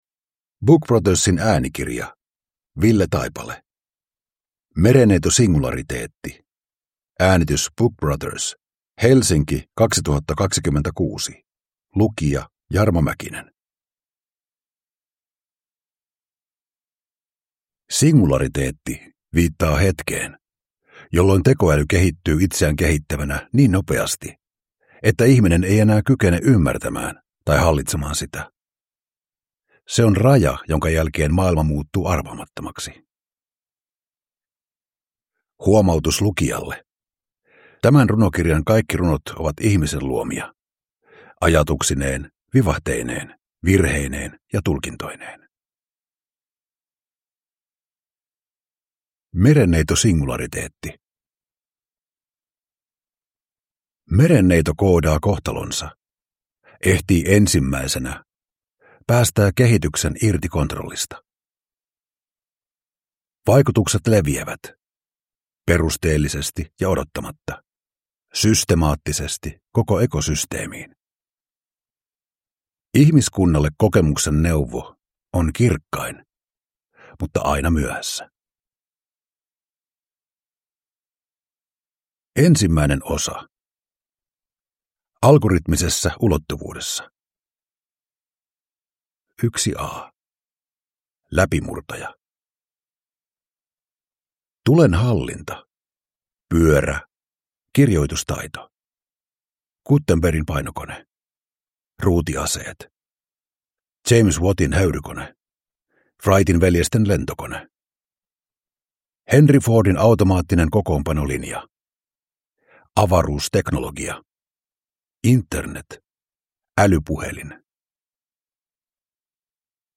Merenneitosingulariteetti – Ljudbok